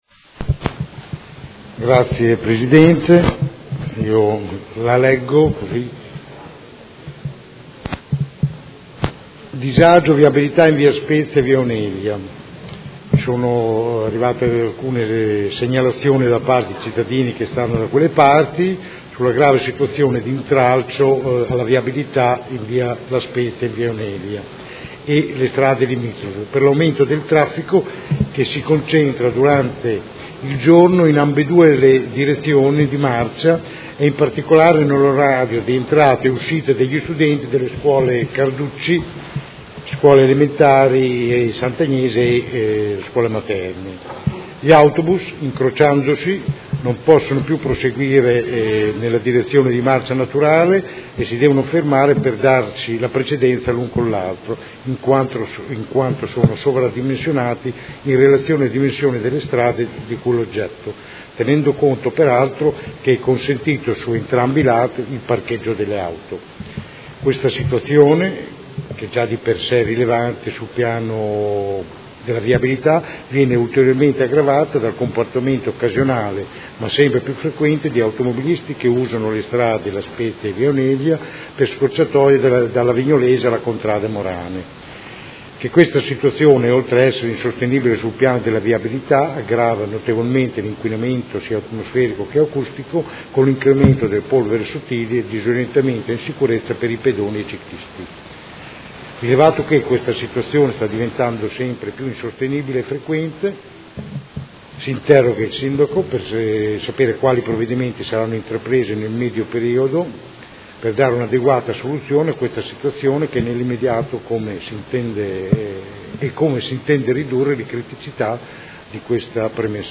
Seduta del 21/12/2017. Interrogazione del Consigliere Rocco (Art.1-MDP/Per Me Modena) avente per oggetto: Disagio viabilità Via La Spezia/Via Oneglia